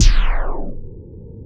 start-slow-down.ogg